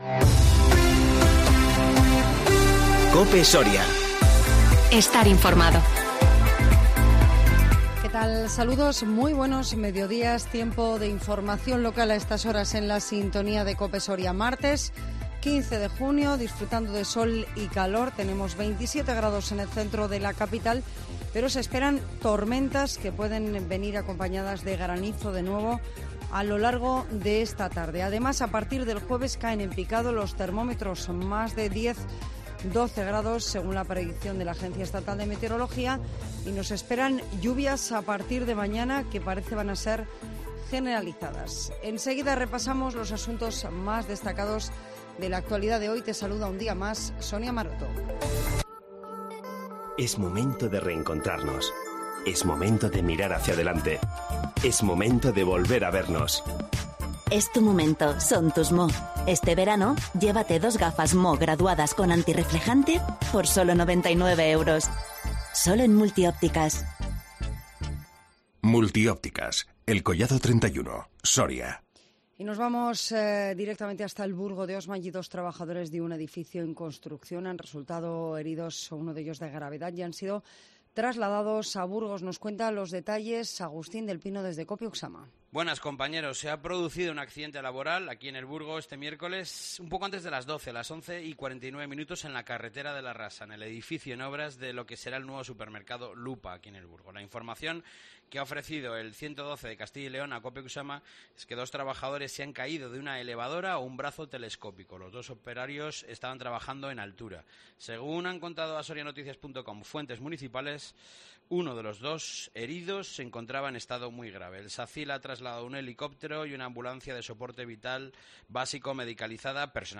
INFORMATIVO MEDIODÍA 15 JUNIO 2021